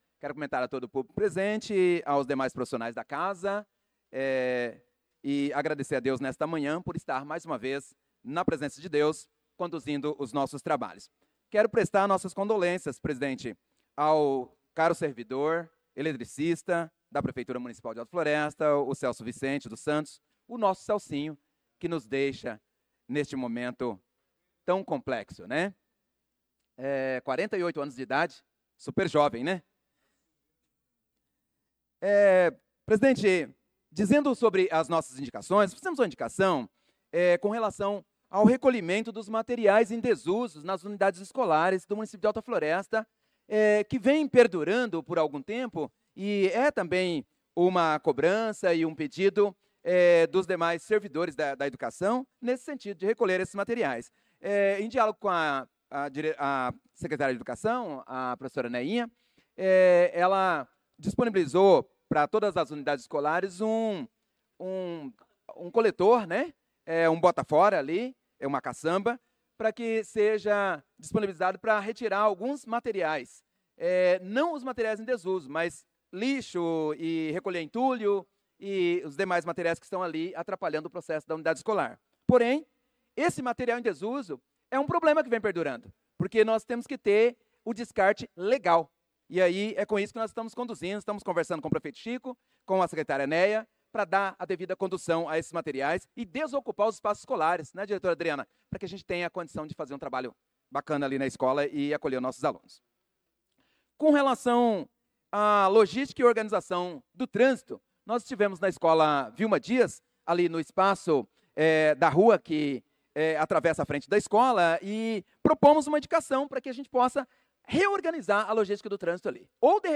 Pronunciamento do vereador Professor Nilson na Sessão Ordinária do dia 04/08/2025.